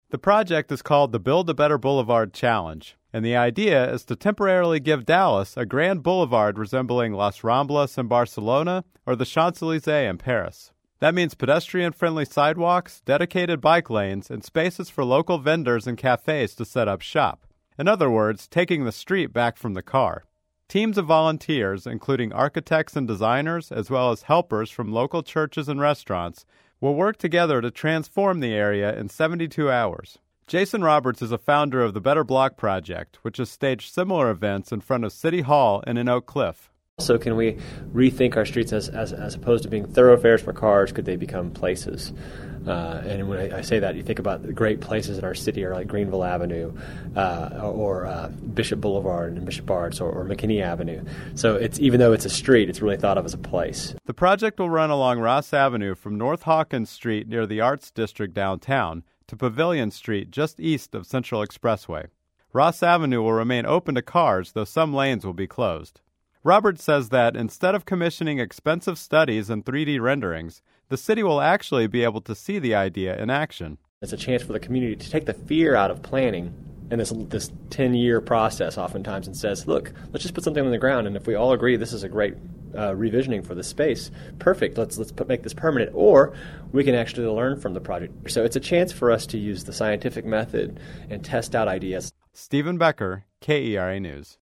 • KERA Radio story: